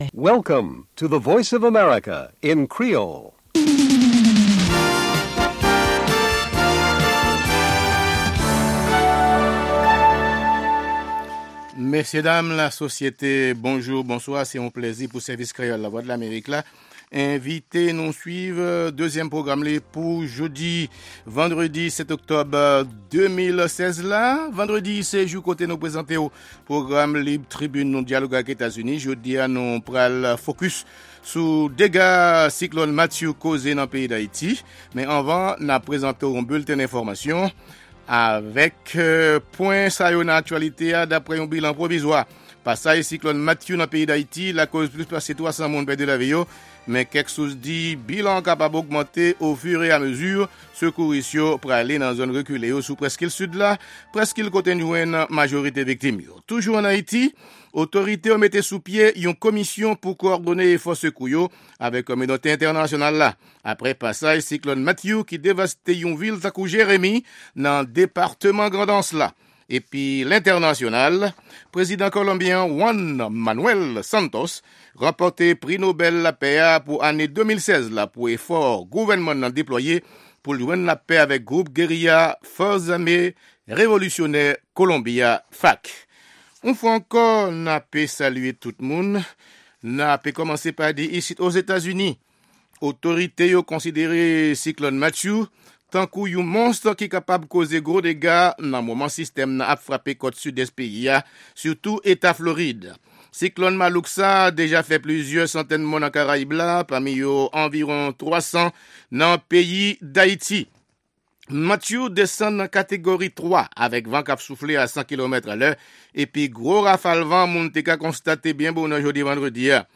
Se 2èm pwogram jounen an, avèk nouvèl tou nèf sou Lèzetazini, Ayiti ak rès mond la. 2 fwa pa semèn (mèkredi ak vandredi) se yon pwogram lib tribin "Dyaloge ak Etazini", sou Ayiti oubyen yon tèm enpòtan konsènan Lèzetazini ou rejyon Amerik Latin nan.